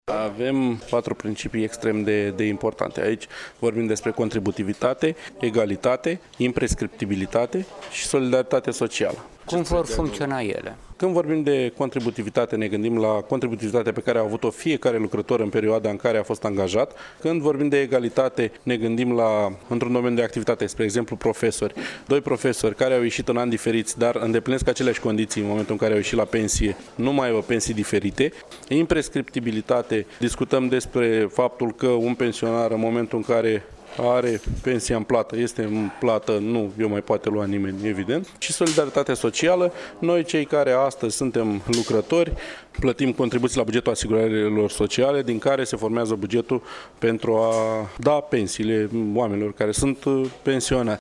El a participat la dezbaterea organizată pe marginea prezentării viitoarei legi, dezbatere la care au fost prezenţi reprezentanţi ai ligilor şi asociaţiilor de pensionari din judeţ.